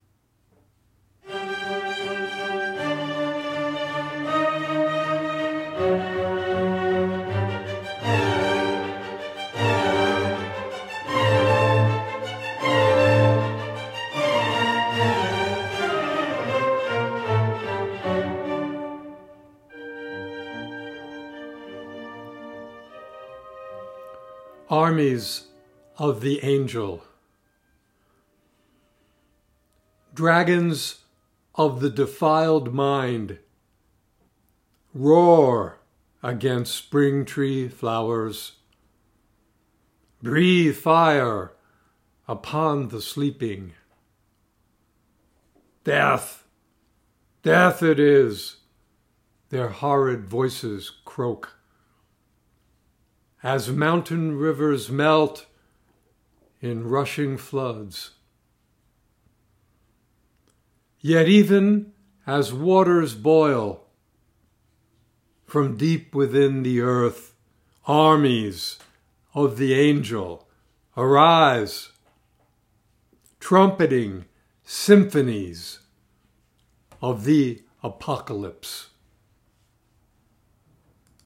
Reading of “Armies of the Angel” with music by Mozart